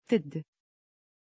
Pronunciation of Teddie
pronunciation-teddie-tr.mp3